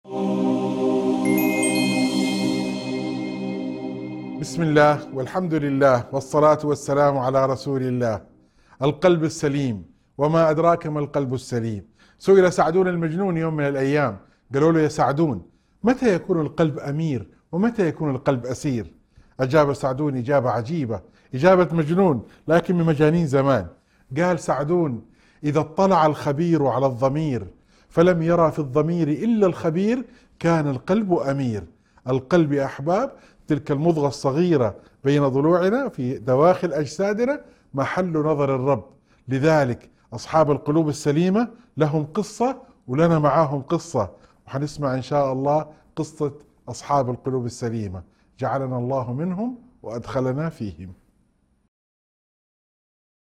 موعظة مؤثرة تتحدث عن القلب السليم ومتى يكون حراً أميراً ومتى يكون أسيراً، مستشهدة بكلام سعدون المجنون. تدعو إلى التعلق بالخبير سبحانه وتعالى وتذكر بقصة أصحاب القلب السليم، مع الدعاء بأن يجعلنا الله منهم.